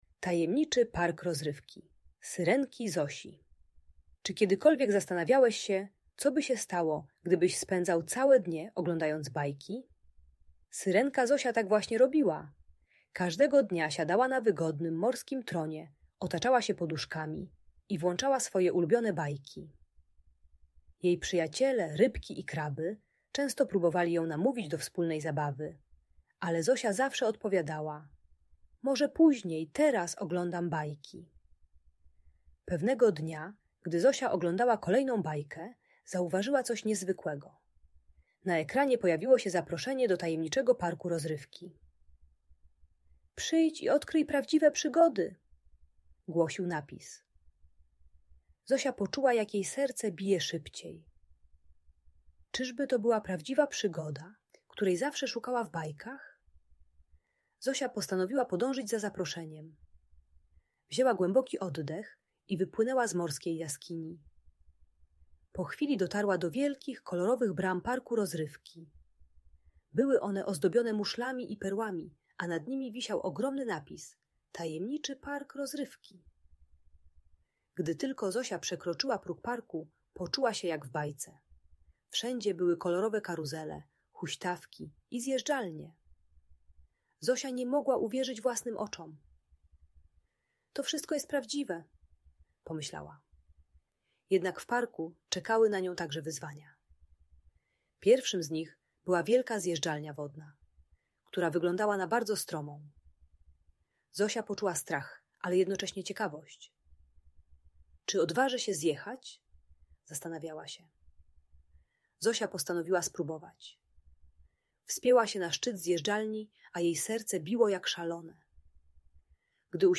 Tajemniczy Park Rozrywki Syrenki Zosi - Audiobajka dla dzieci